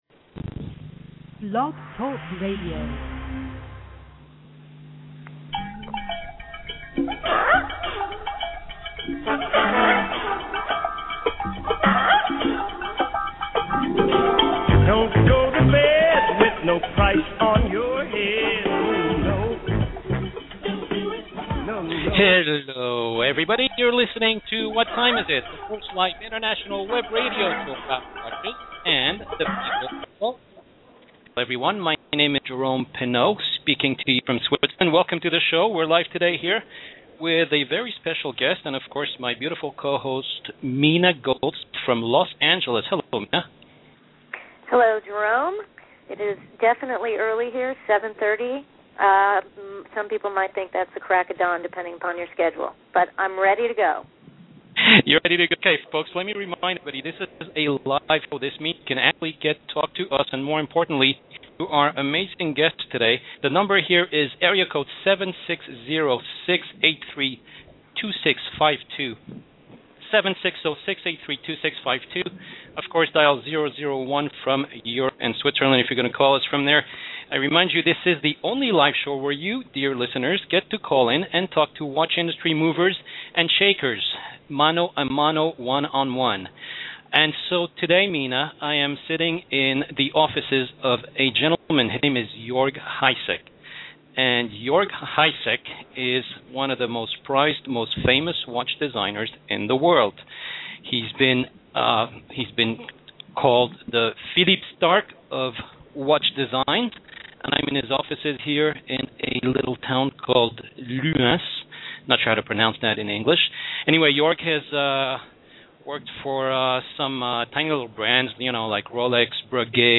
“What Time Is It?” is the first live international web radio show about watches and the people who love them!